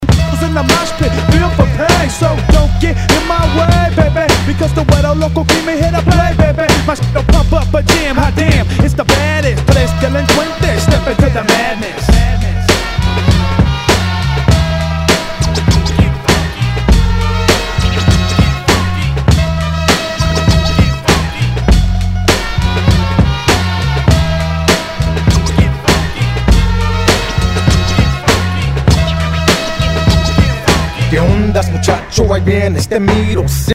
Tag       HIP HOP HIP HOP